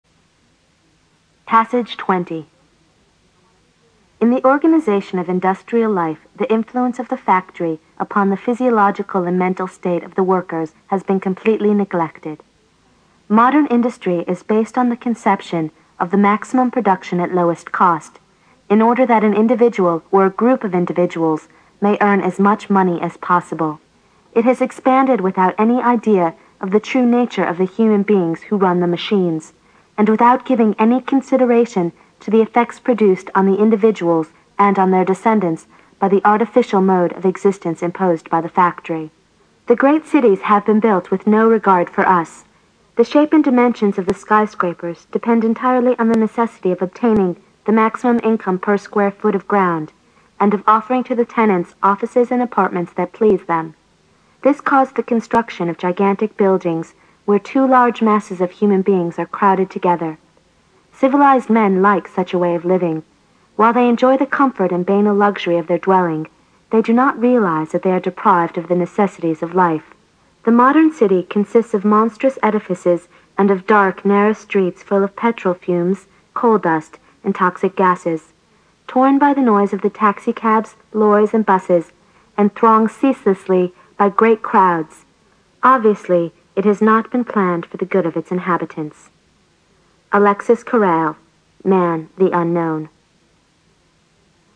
新概念英语85年上外美音版第四册 第20课 听力文件下载—在线英语听力室